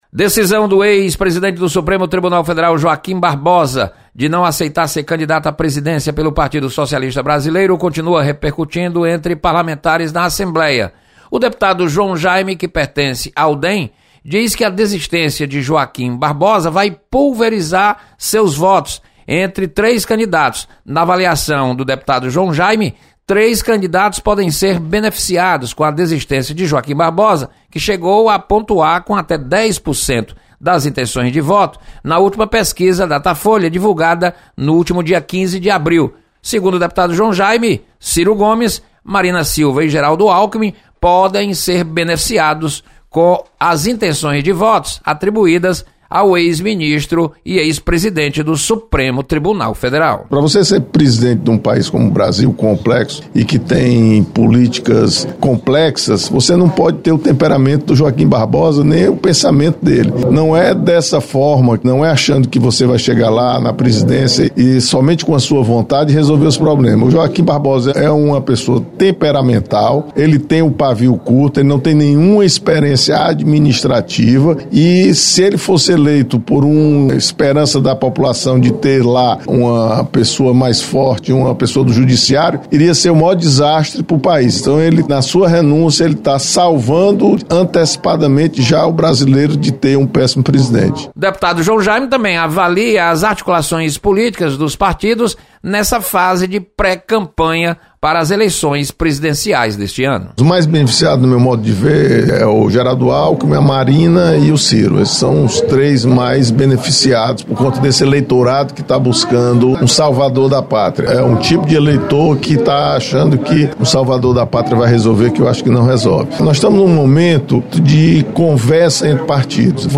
Deputados comentam sobre encaminhamento de processos envolvendo políticos para a primeira instância.